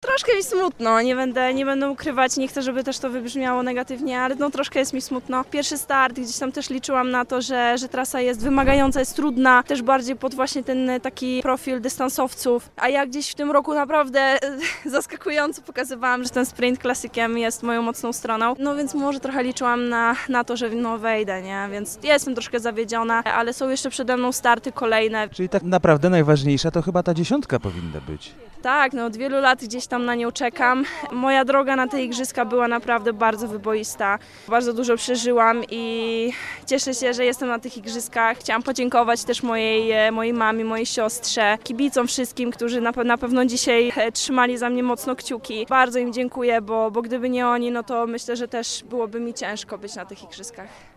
w rozmowie